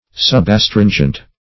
subastringent - definition of subastringent - synonyms, pronunciation, spelling from Free Dictionary
Subastringent \Sub`as*trin"gent\, a.